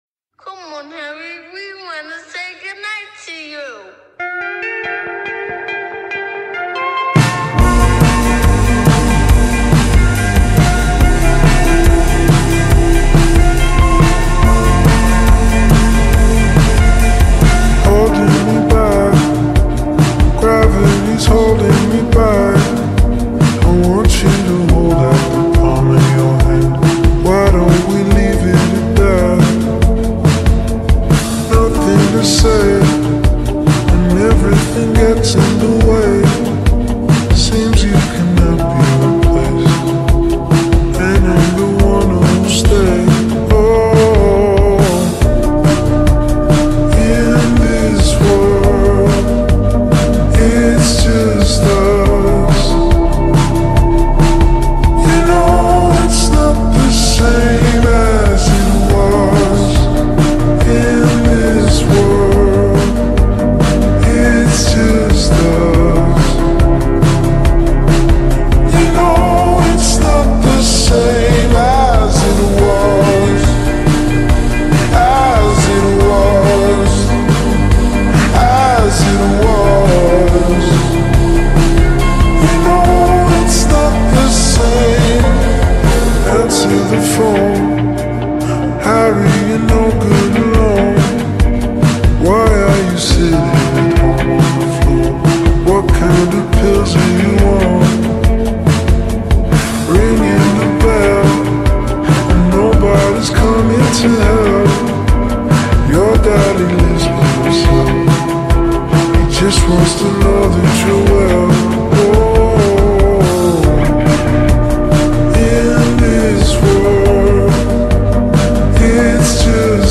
با ریتمی آهسته شده
غمگین